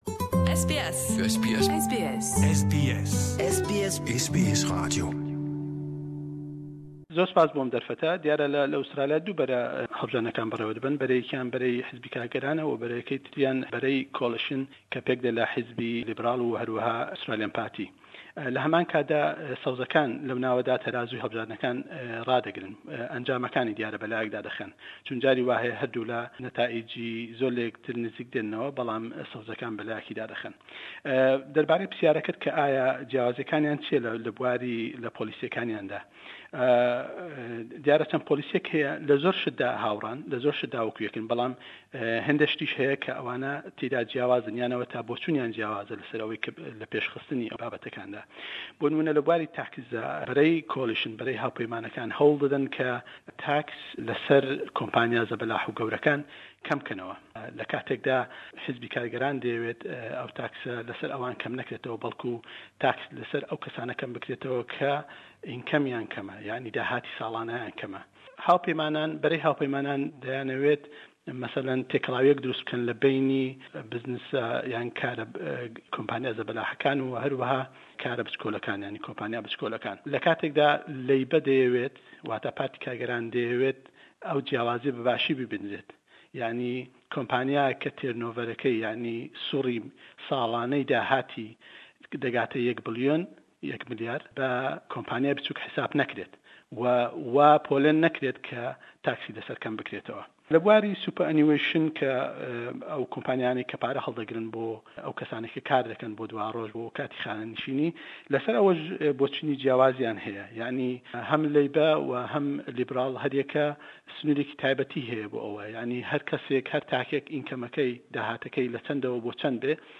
Kampîn kirdin bo hellbijartinekanî federalî Australya le layen parte siyasêkanewe berdewame bo rojî dengdan le 2î mangî 7 da. Le em hevpeyvîn-man le gell rojnemewan